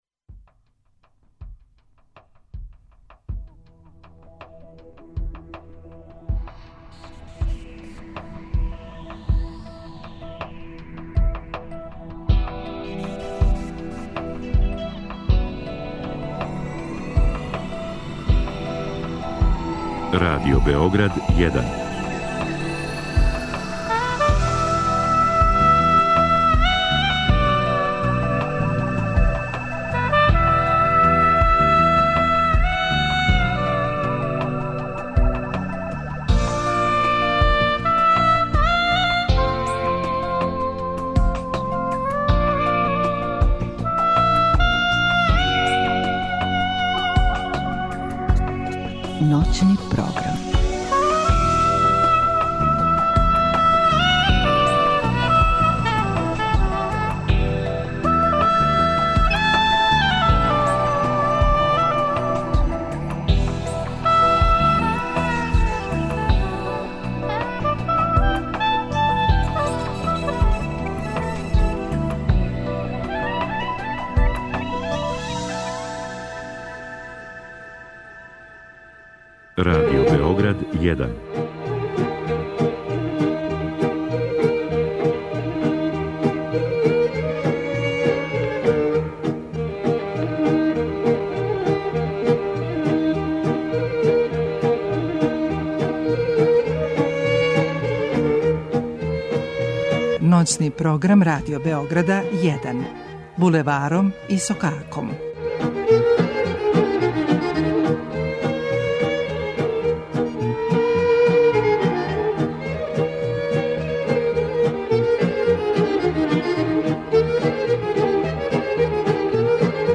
Слушаоци нам шаљу електронску пошту, а ми их будимо и разговарамо са њима...
Јутро ћемо сачекати уз босанске севдалинке и надахнуту народну музику из Македоније.